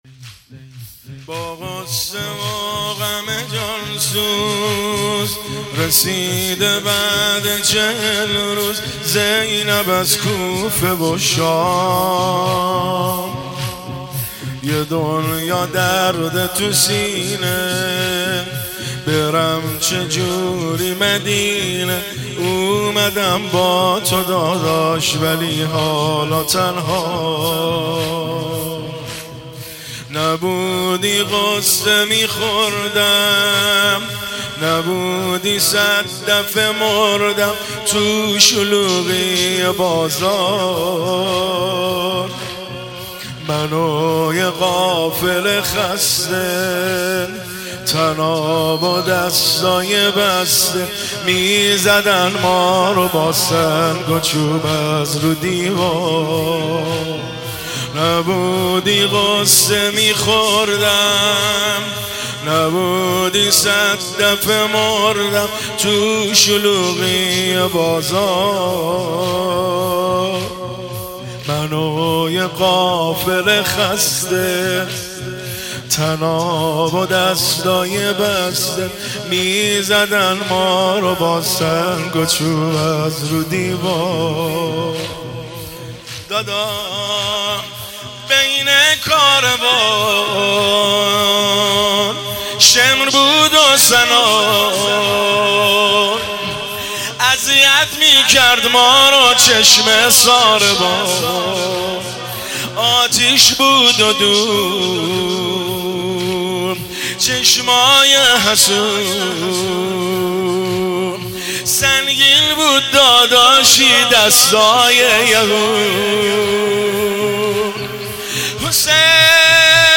زمینه – شب اربعین حسینی 1400